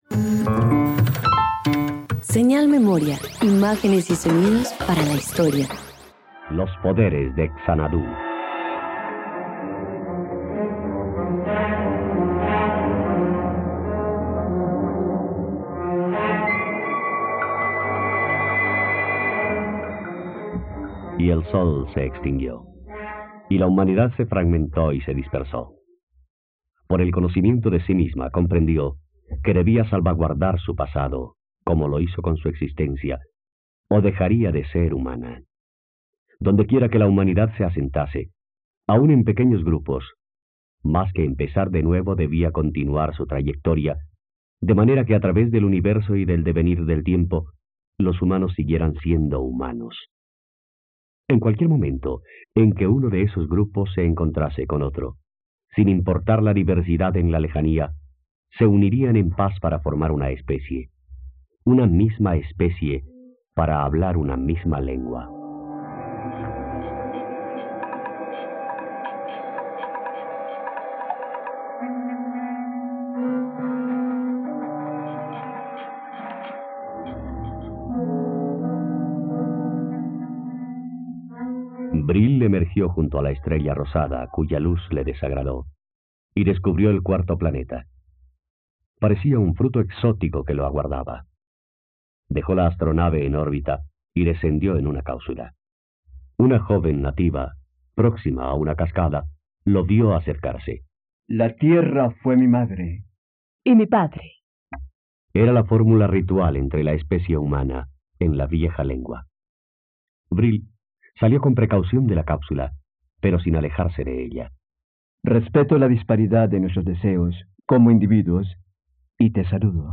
Los poderes de Xanadú - Radioteatro dominical | RTVCPlay